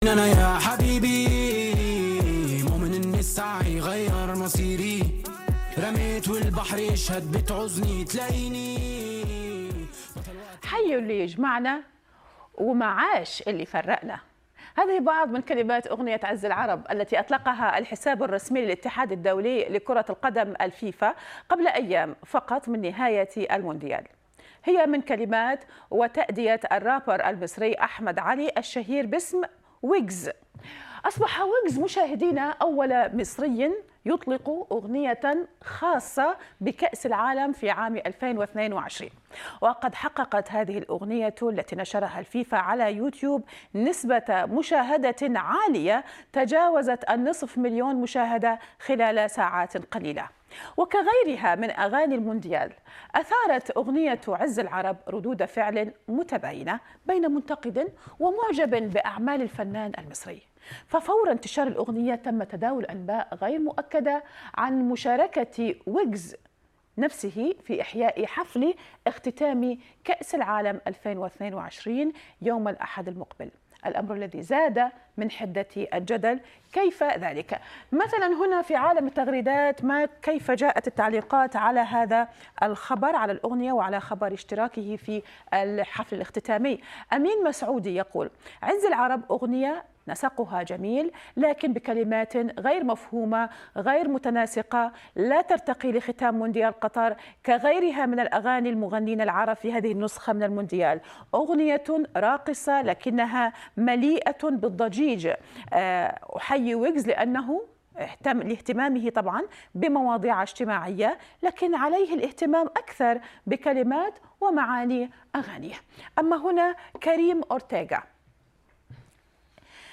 الرابر المصري
الفنان المصري والرابر